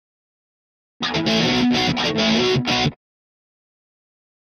Guitar Rock Finale Rhythm Version 1